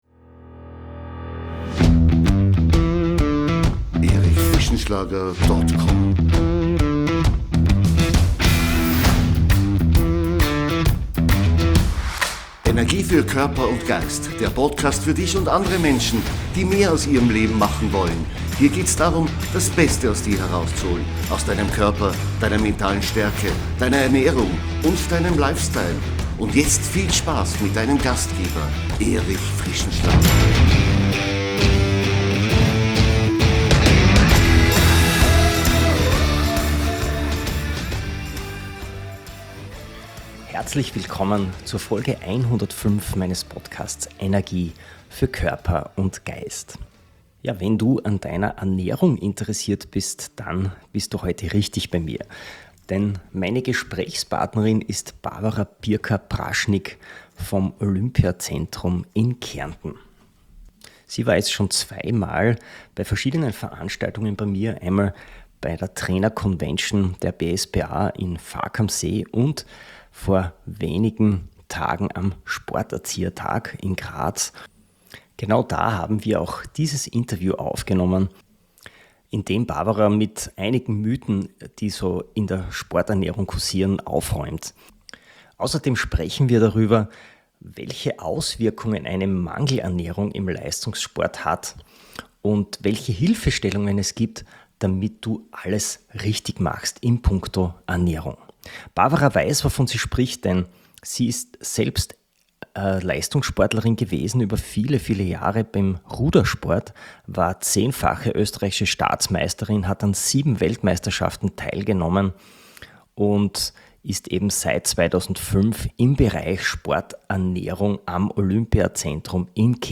Ein spannendes Gespräch für alle, die mehr über neurozentriertes Training und dessen Anwendung im modernen Leistungs- und Rehasport erfahren möchten!